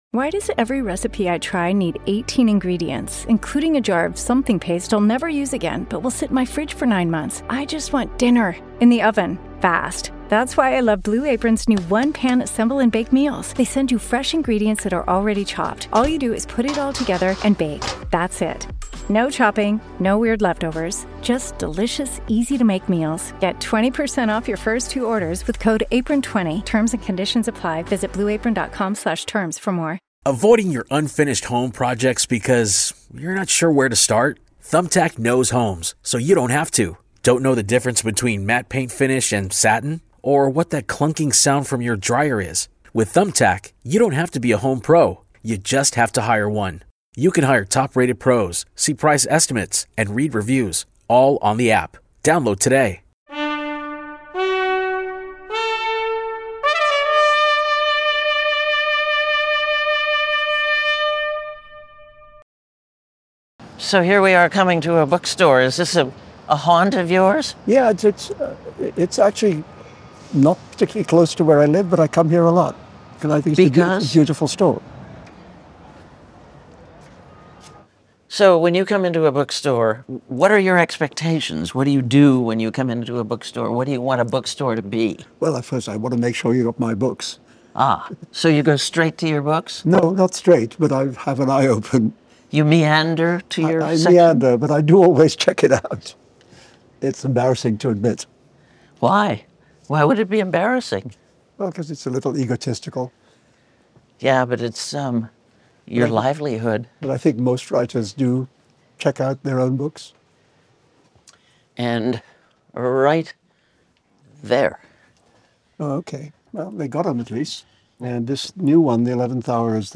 Extended Interview: Salman Rushdie